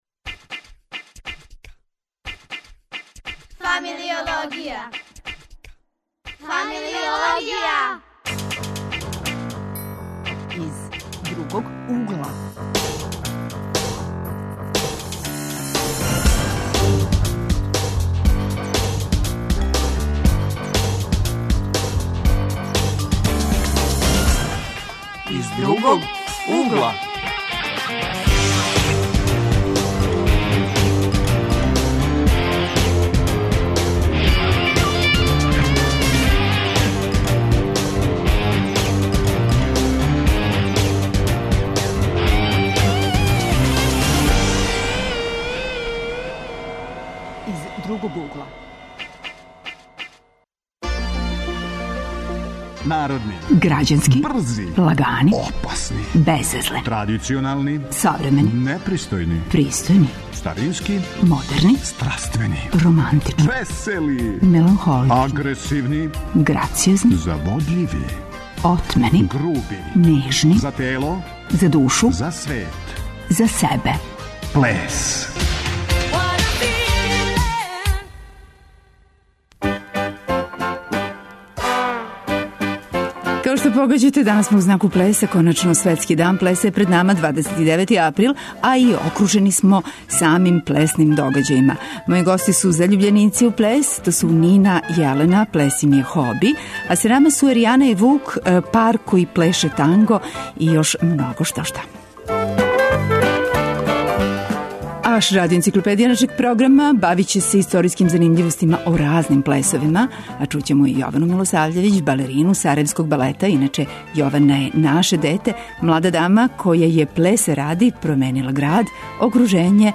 Гости су нам средњошколци и студенти, заљубљеници у плес, неки од њих чак зарађују свој џепарац играјући, а неки захваљујући плесу путују по свету.